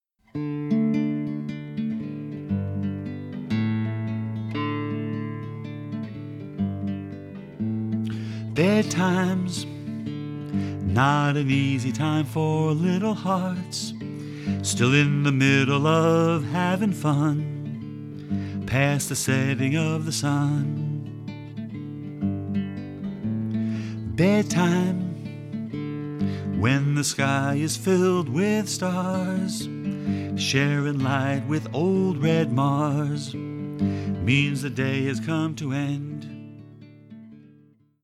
Quietly sit and act out the story of this bedtime lullaby.